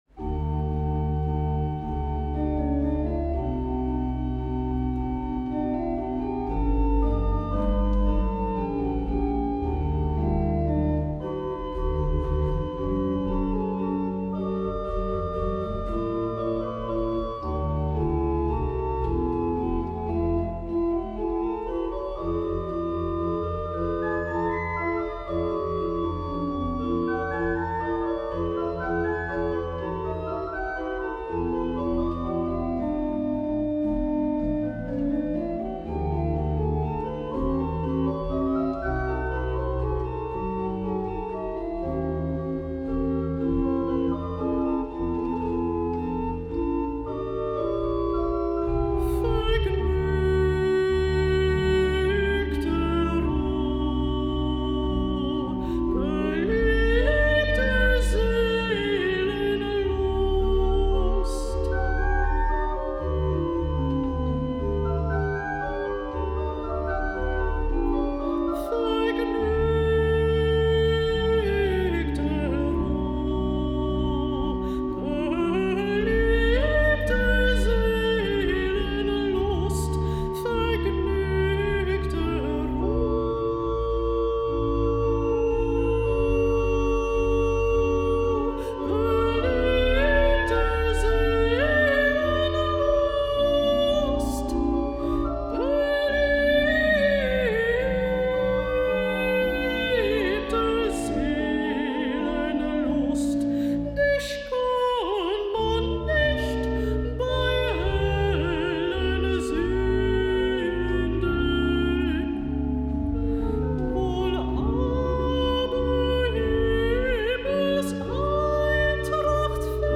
Locatie: Paradijskerk, Rotterdam
Paradijskerk-Bach-opname.mp3